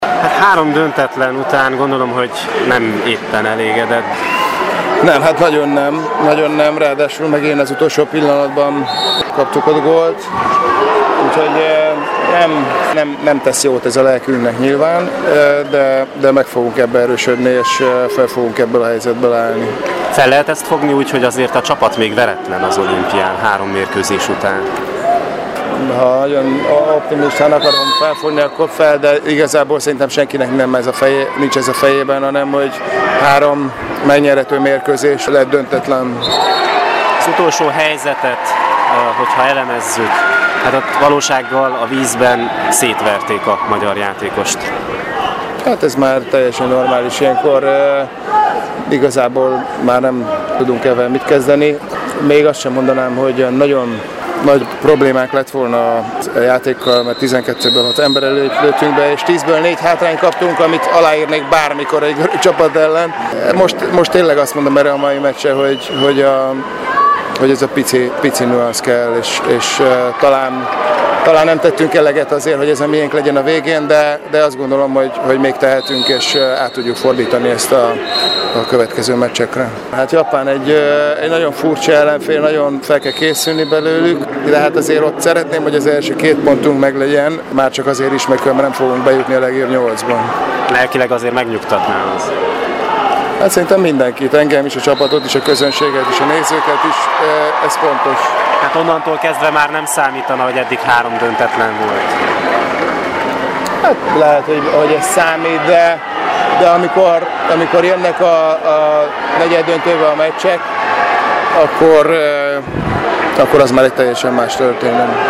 Mérkőzés után a Marosvásárhelyi Rádiónak Benedek Tibor szövetségi kapitány nyilatkozott: